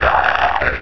pain1.wav